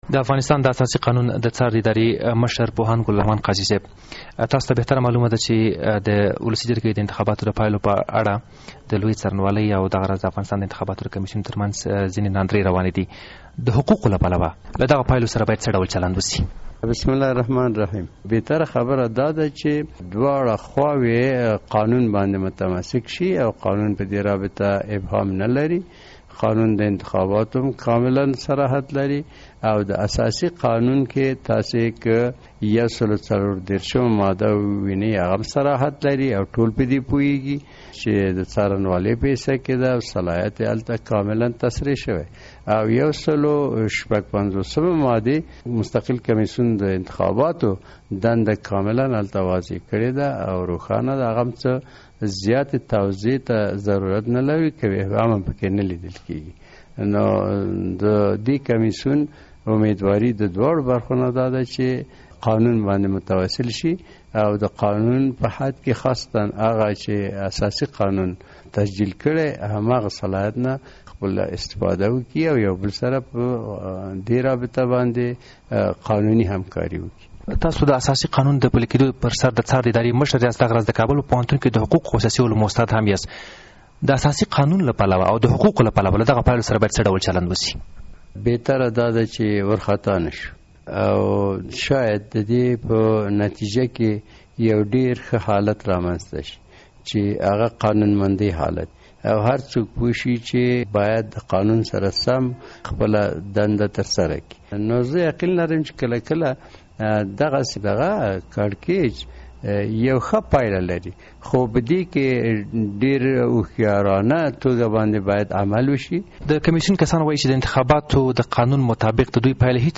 له ګل رحمن قاضي سره مرکه